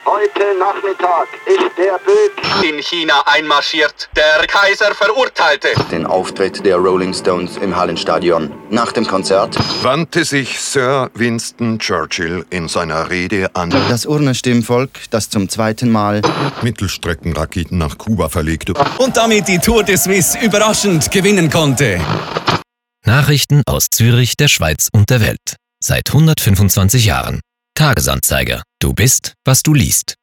Die Kampagne umfasst fünf Sujets und wird von zwei Radiospots ergänzt, welche die Tagesaktualität und thematische Breite der Zeitung humorvoll dramatisieren. Im Stile collageartiger Nachrichtenmeldungen wechseln die Sprecher mitten im Satz das Thema und den dazugehörigen Tonfall – von schneidend zu schwärmend, von nüchtern zu euphorisch – und streichen so hervor, dass man im «Tagi» über alle Lebensbereiche umfassend informiert wird.